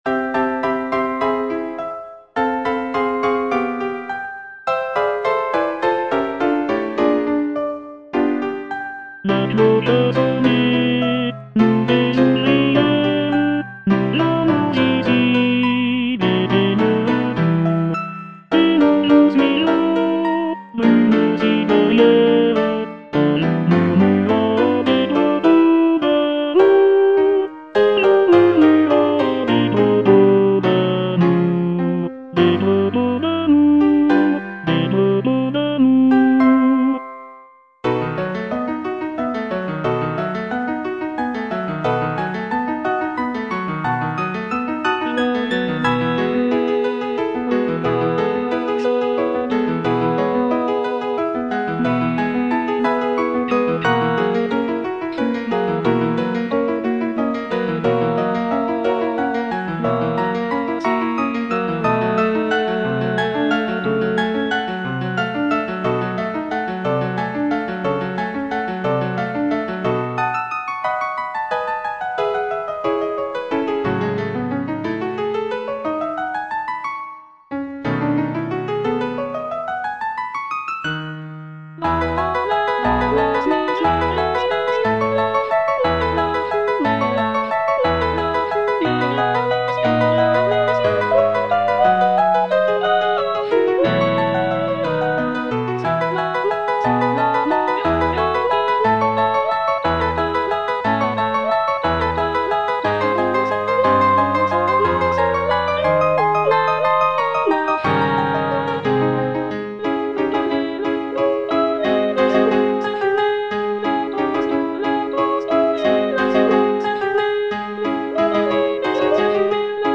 G. BIZET - CHOIRS FROM "CARMEN" Chorus of cigarette-girls - Tenor (Emphasised voice and other voices) Ads stop: auto-stop